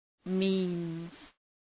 Shkrimi fonetik {mi:nz}